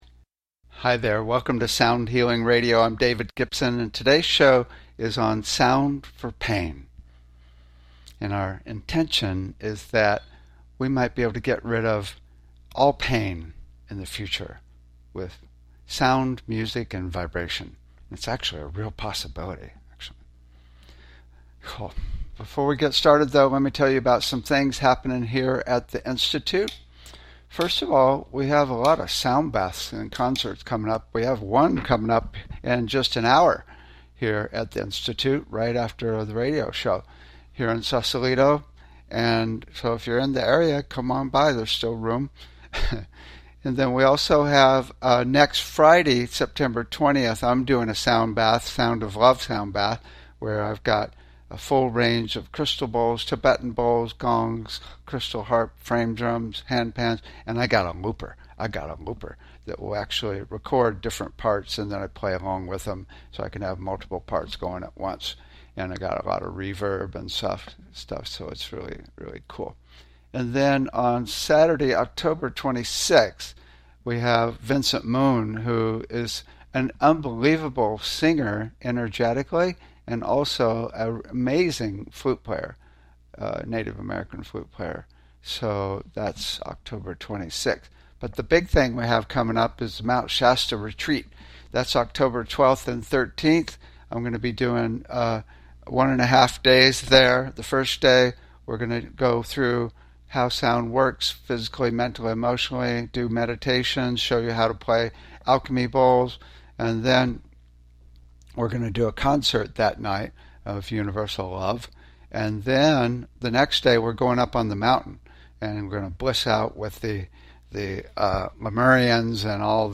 Talk Show Episode, Audio Podcast, Sound Healing and Sound For Pain, to get rid of all pain in the future, with sound, music, and vibration on , show guests , about sound for pain,get rid of all pain,with sound music and vibration, categorized as Education,Energy Healing,Sound Healing,Love & Relationships,Emotional Health and Freedom,Mental Health,Science,Self Help,Spiritual